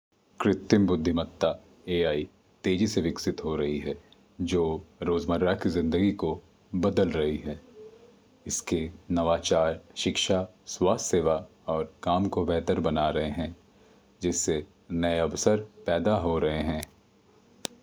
speech
emotional-speech
natural-speech